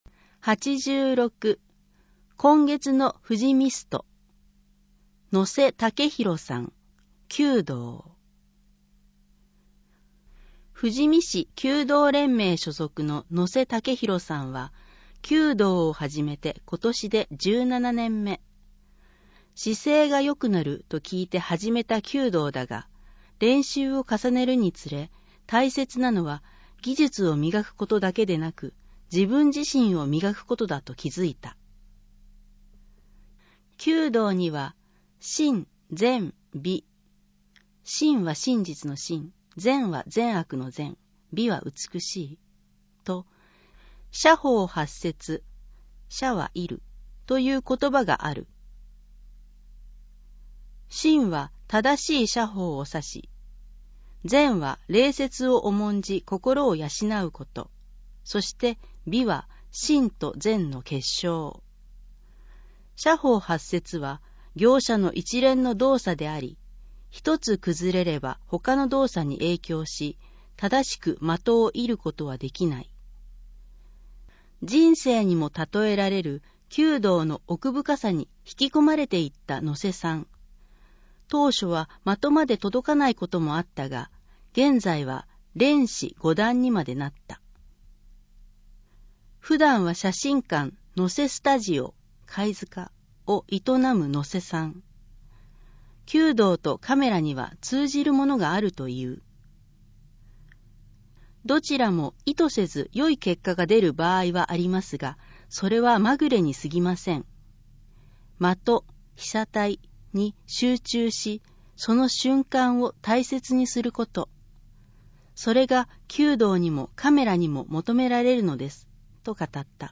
新春対談（音楽ファイル(MP3)：7,484KB） 2-9ページ